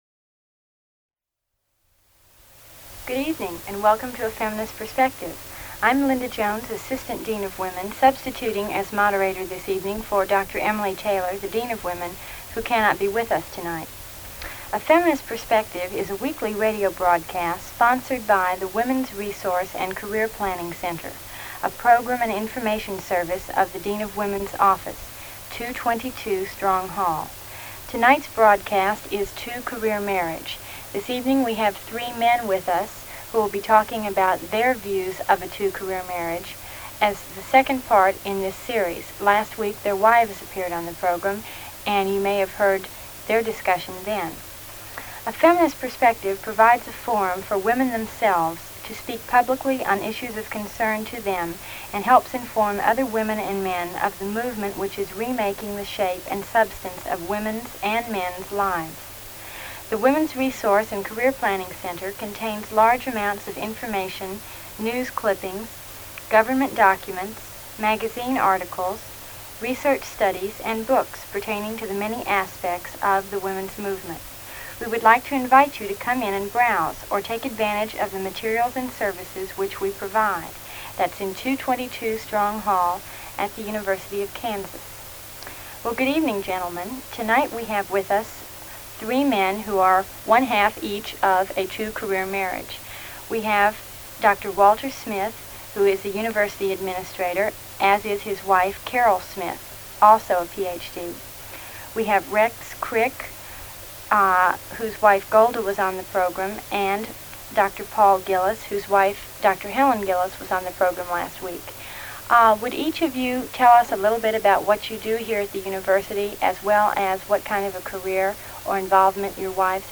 Radio talk shows